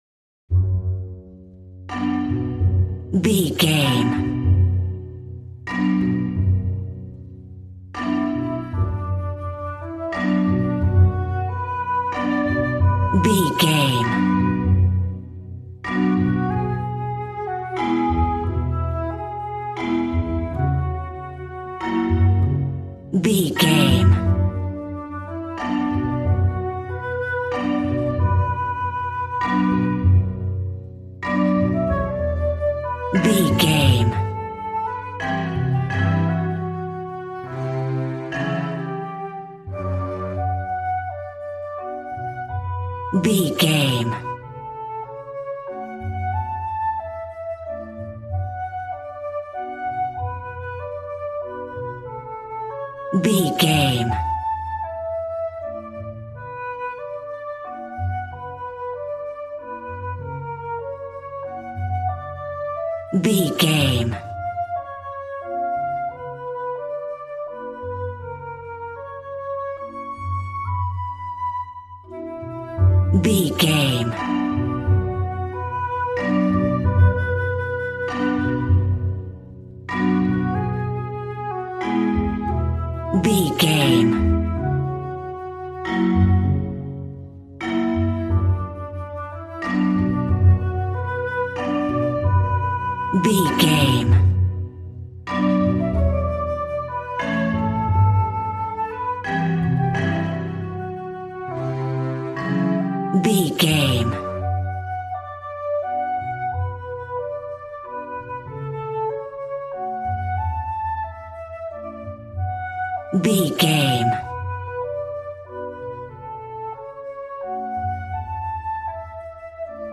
Aeolian/Minor
cheerful/happy
joyful
drums
acoustic guitar